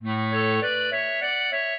clarinet
minuet5-4.wav